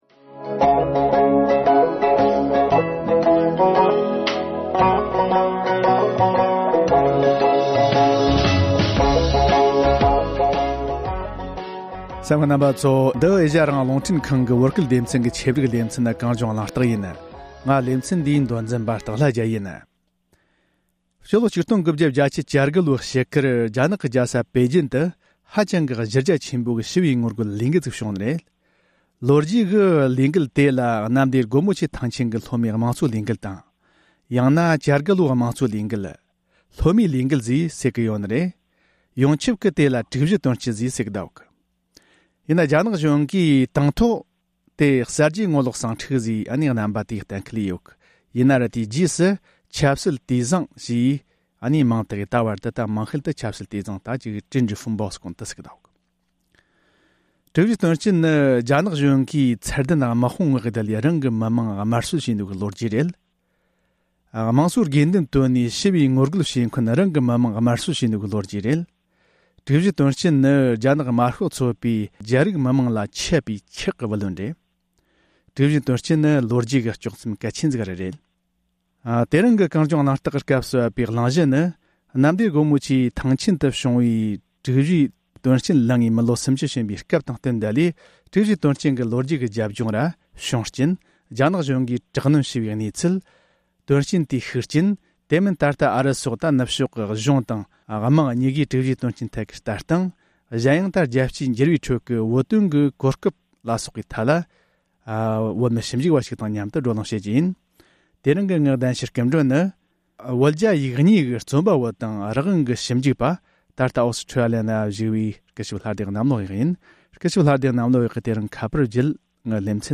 བགྲོ་གླེང